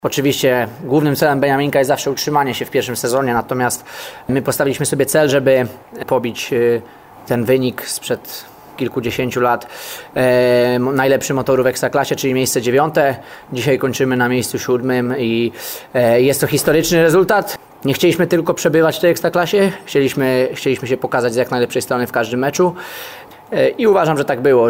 KONFERENCJA-PRASOWA-Po-meczu-z-Radomiakiem-Radom_01.mp3